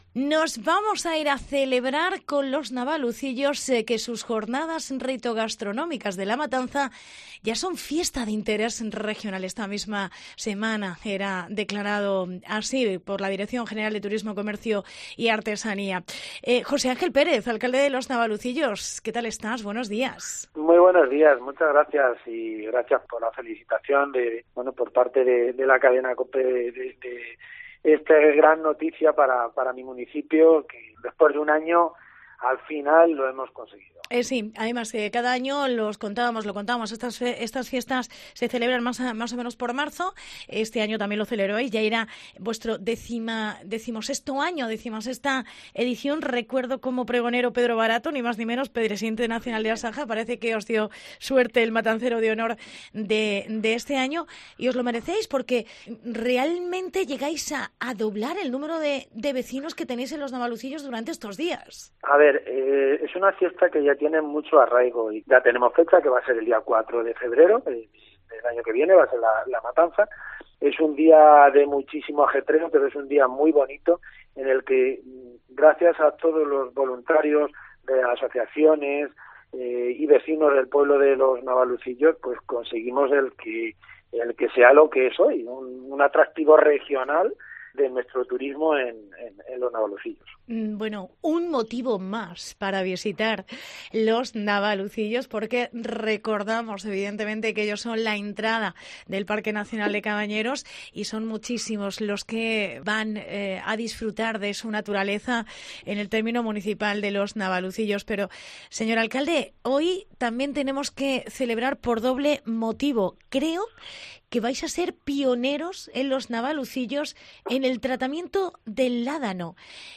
Entrevista José Ángel Pérez. Alcalde de los Navalucillos